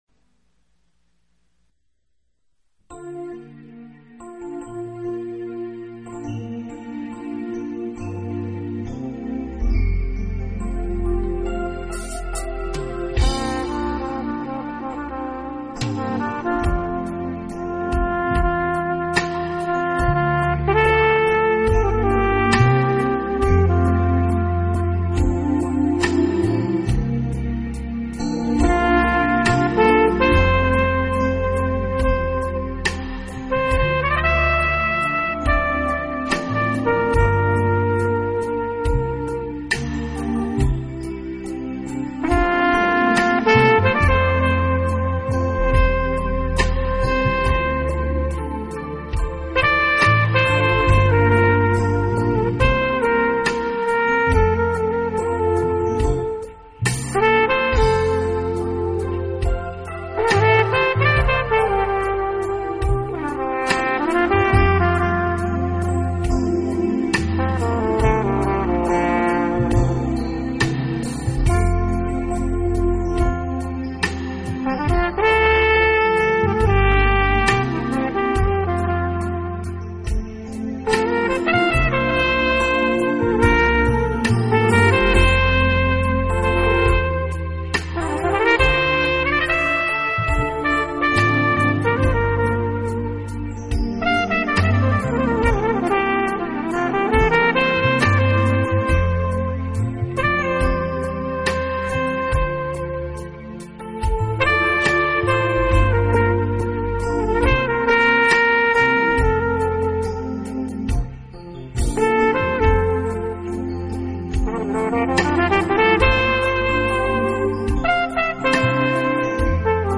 音乐类型：Blues/New Age
这号声清雅、肃穆；这号声激情、回旋；这号声委婉、轻柔。